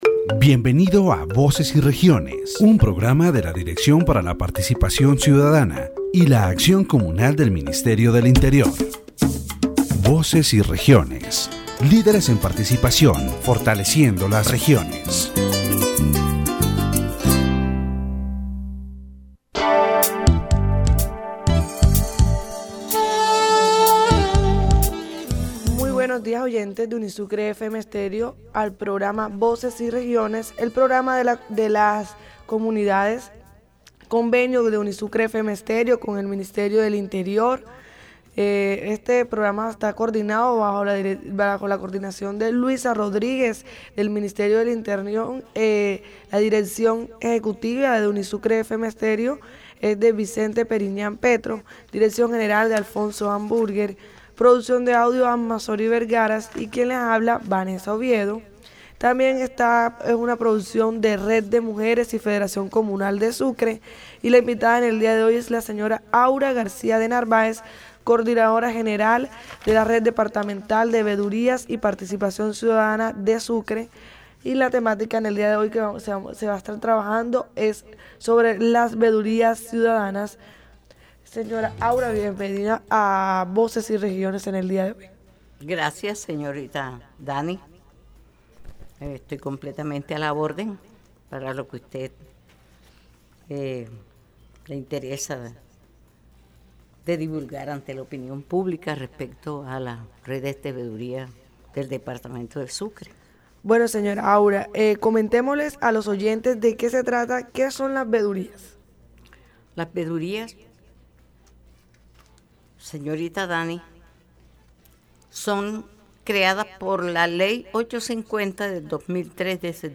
It is important to note that the interview is conducted in colloquial language and with specific references to the Sucre region, which may be difficult to understand for people unfamiliar with this context.